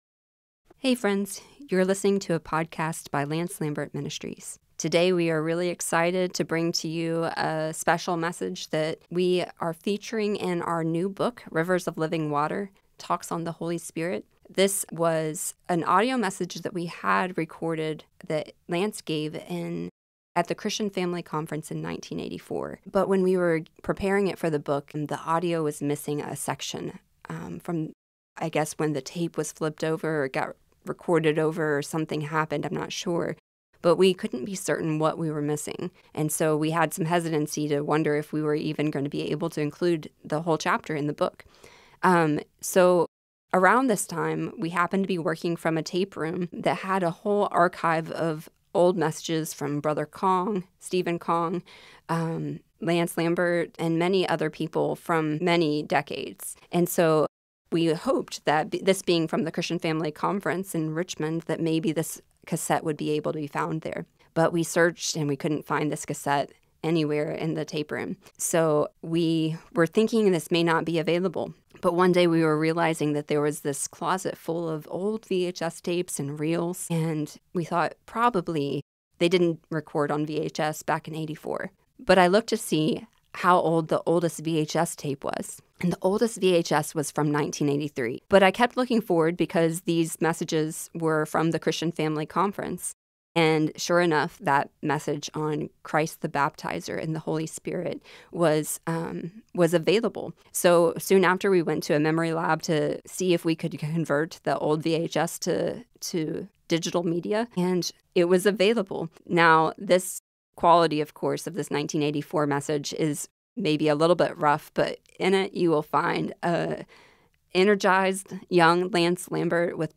He argues that nothing in Christian life, from conversion to church life to spiritual gifts, is possible without the Holy Spirit's work. This Holy Spirit baptism message was recovered from VHS footage after the original audio was damaged.